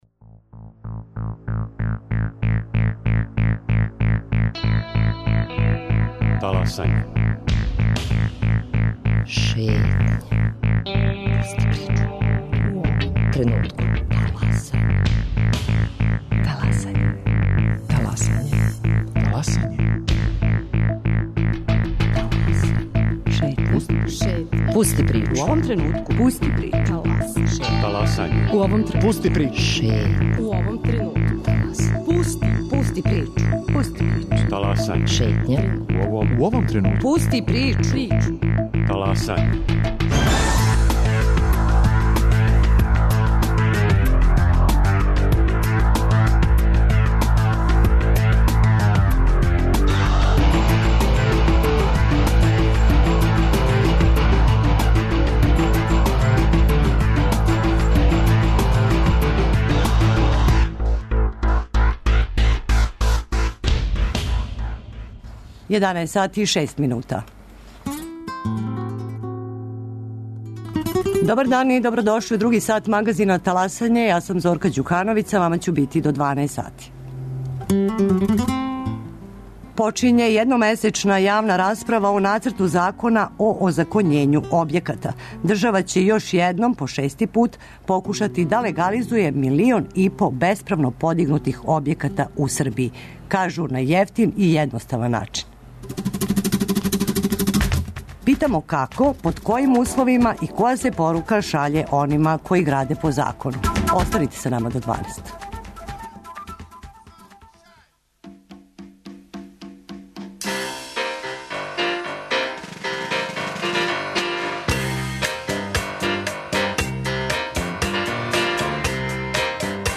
Гост у студију